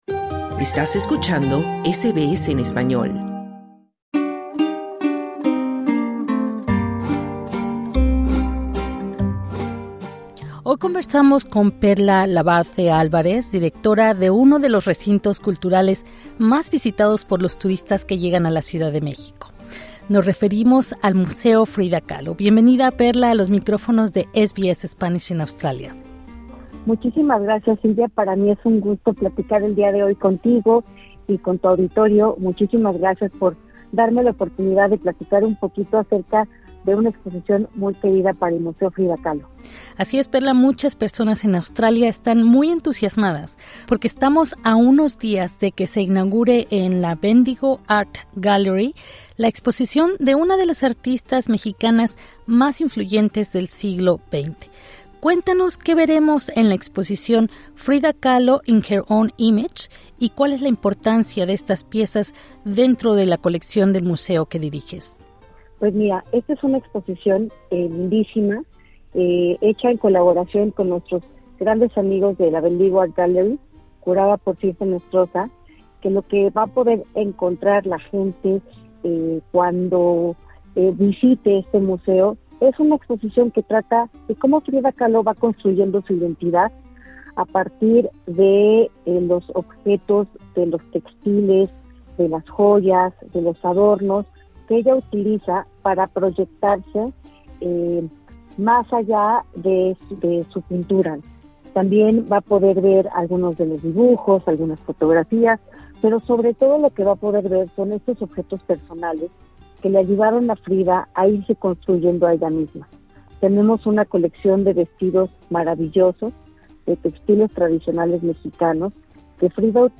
Escucha la entrevista en el podcast que se encuentra al inicio de la página.